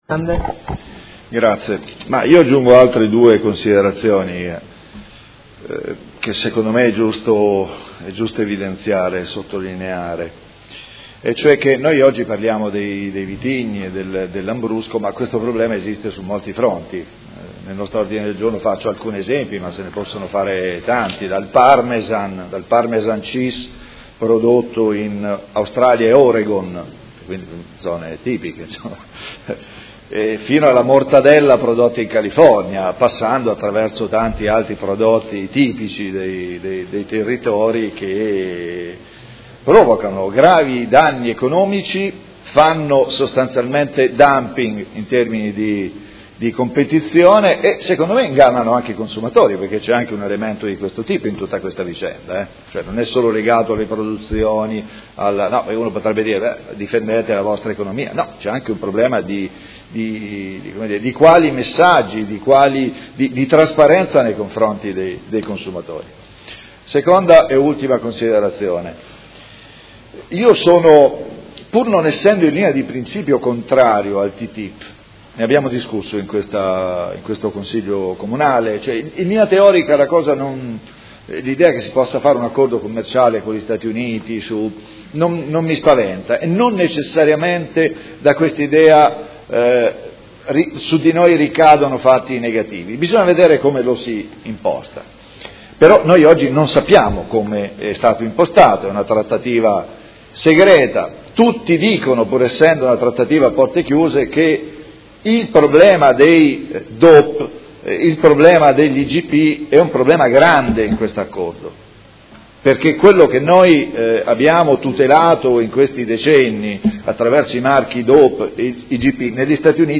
Seduta del 28 gennaio. Discussione su ordini del giorno inerenti la problematica lambrusco